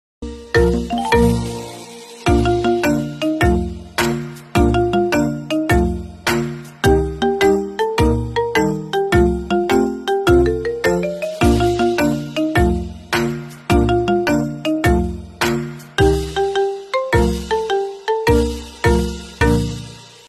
آهنگ قسمت گردان | بی کلام